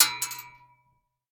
Bullet Shell Sounds
pistol_metal_5.ogg